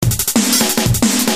Tag: 180 bpm Drum And Bass Loops Drum Loops 230.31 KB wav Key : Unknown